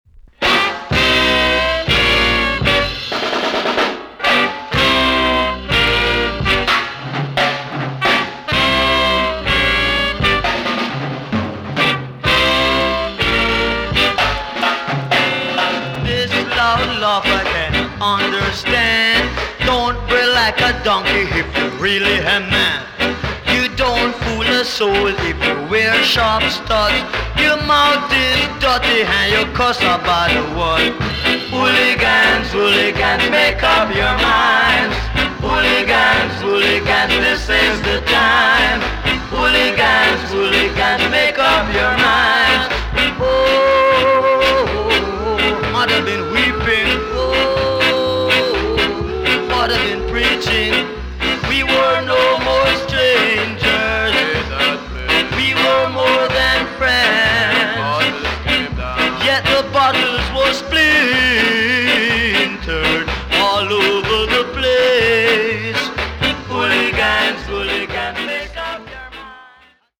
TOP >SKA & ROCKSTEADY
EX- 音はキレイです。